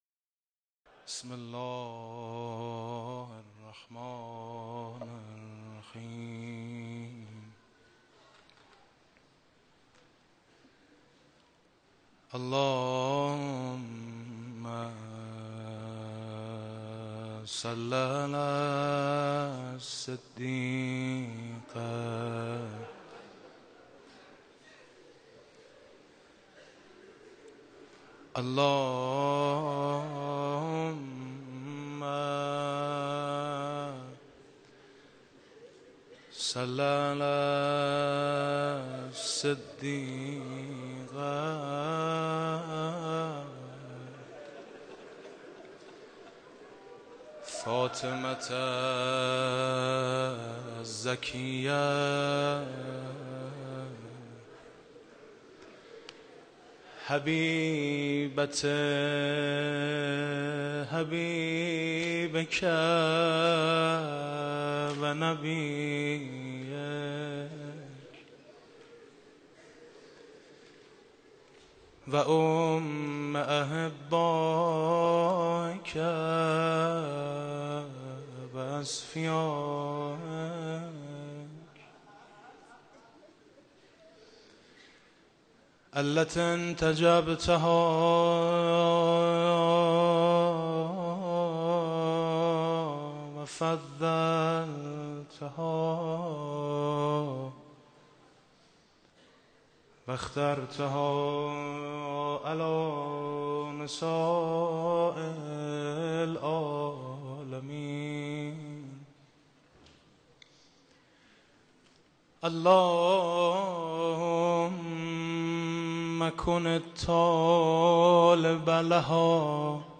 روضه | فکر کردن به غم و غصه مادر سخت است
روضه خوانی حاج میثم مطیعی | فاطمیه سال 1393 | محضر رهبر انقلاب اسلامی | حسینیه امام خمینی(ره)